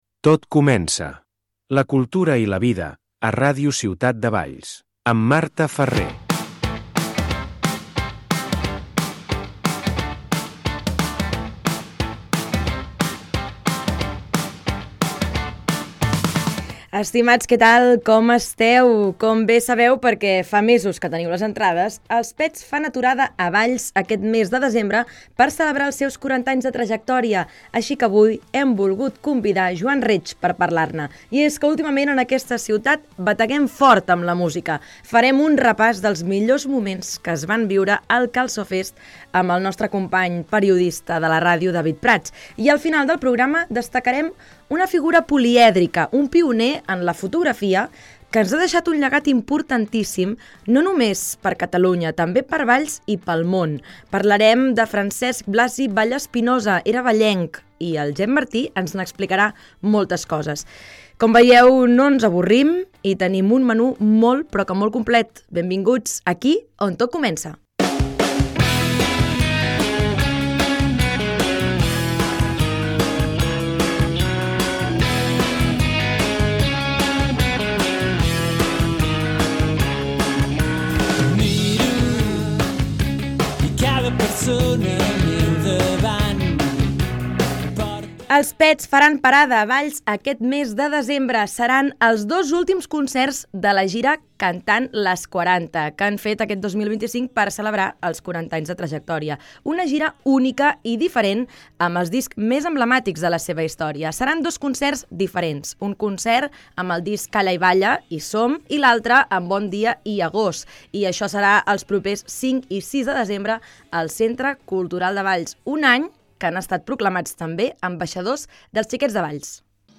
Avui, parlem del concert de “Els Pets” que ja van exhaurir les seves entraqdes a Valls per aquest mes de desembre que celebraran els seus 40 anys de trajectòria. Hem convidat a Joan Reig per parlar-ne. A més, repassarem la figura de Francesc Blasi Vallespinosa, una figura polièdrica i pioner de la fotografia que ens ha deixat un llegat importantíssim, no només per Valls, sinó pel món sencer.